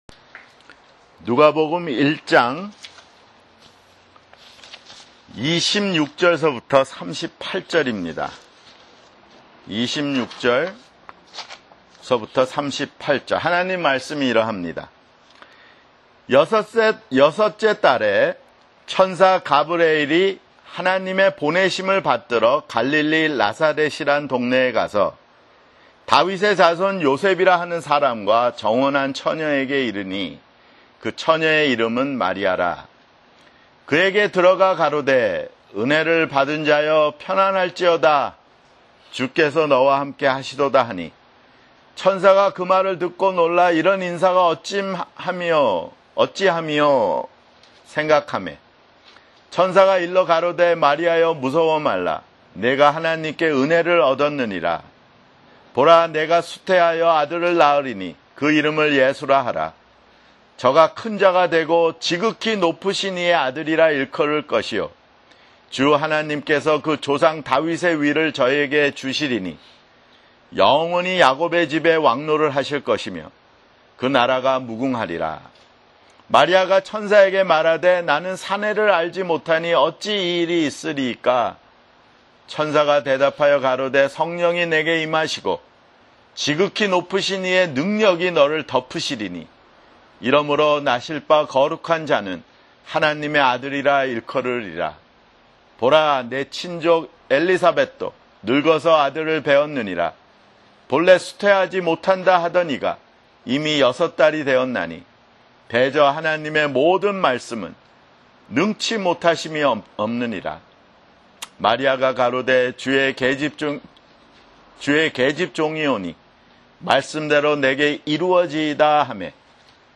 [주일설교] 누가복음 (5)